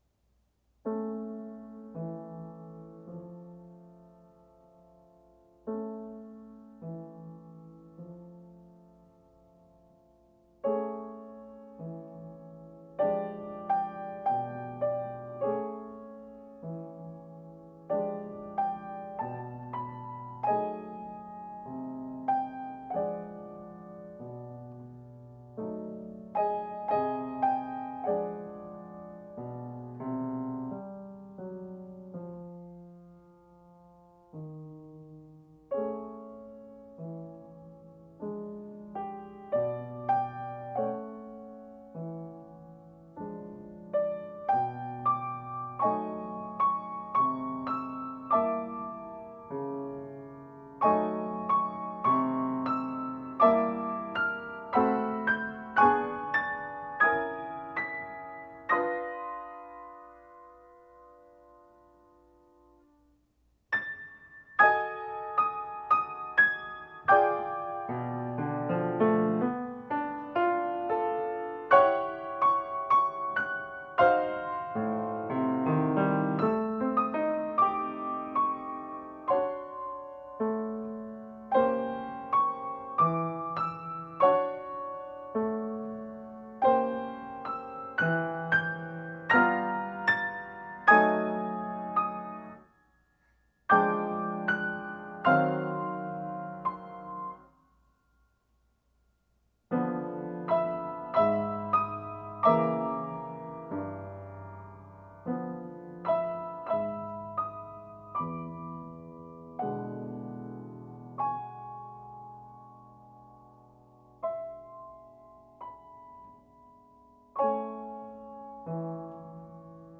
I’ve got as much swing in me as a Corelli concerto grosso.Â But I’ll take those harmonies thank you, jazz.